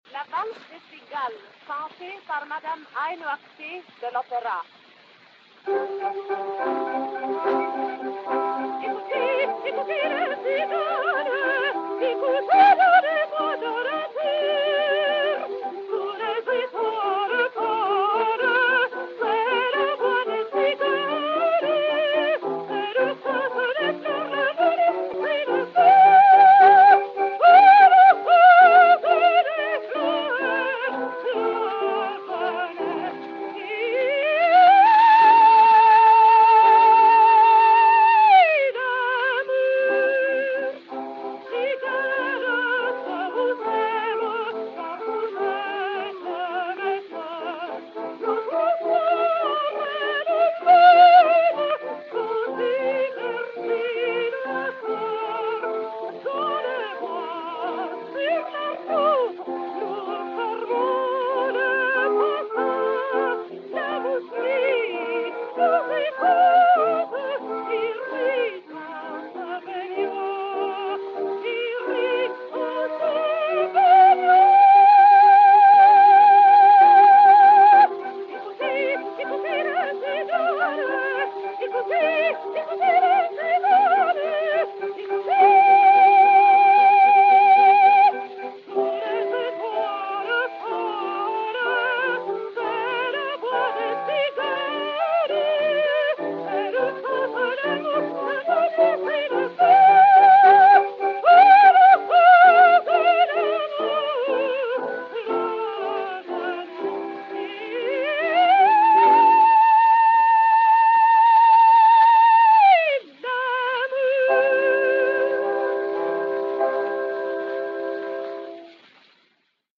Aïno Ackté (Chrysanthème) et Orchestre
Disque Pour Gramophone G.C.-33529, enr. vers 1905